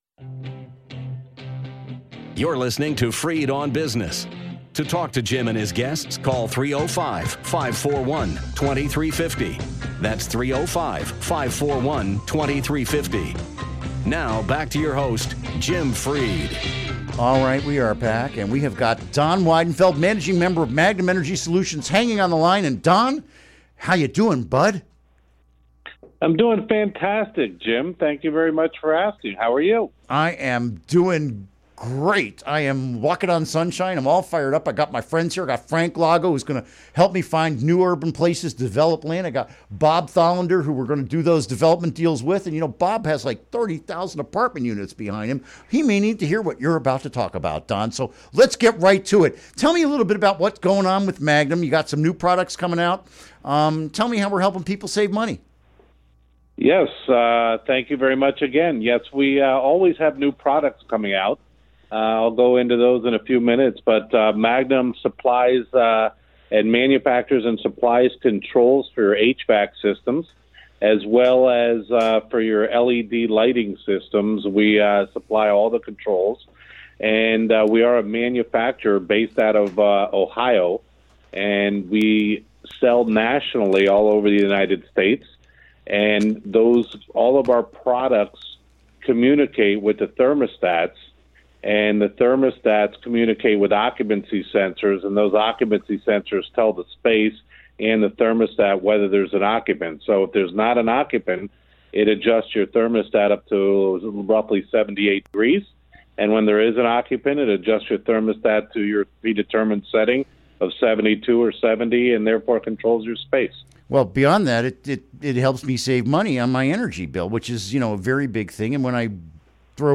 Interview Segment Episode 374: 06-23-16 Download Now!